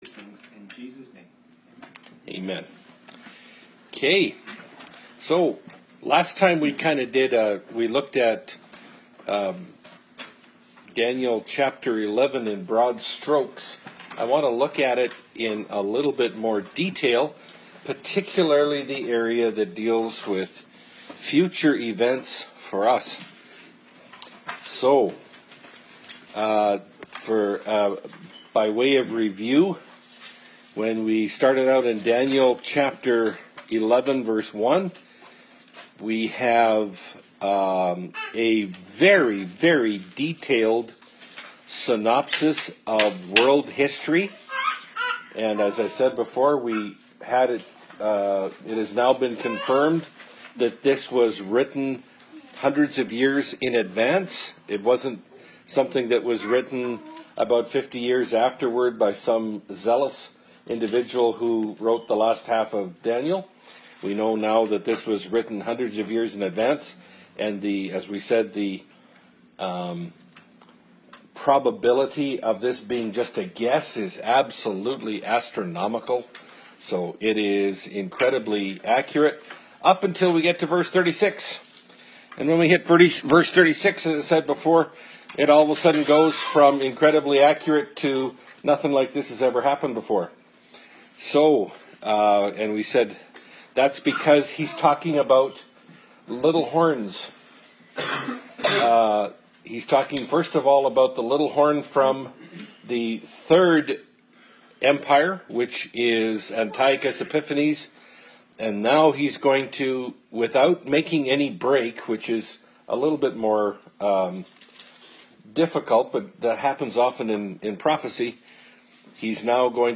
Category: Bible Studies